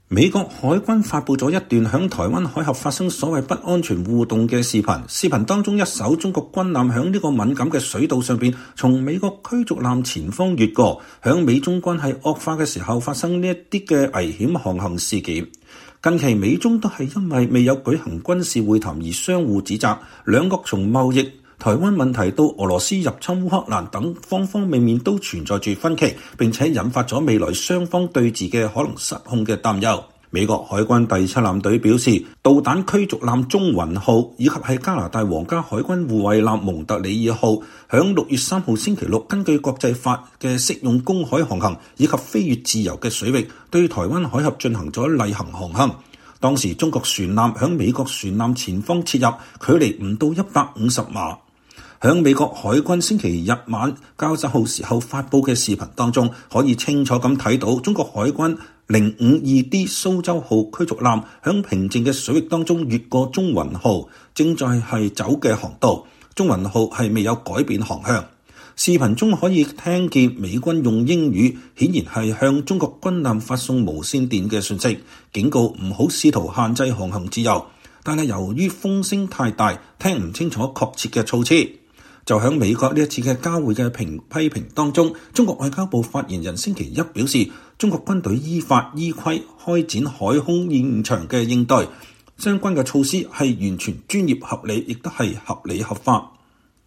視頻中可以聽見美軍用英語，顯然在向中國軍艦發送無線電信息，警告不要“試圖限制航行自由”，但由於風聲太大聽不清楚確切措辭。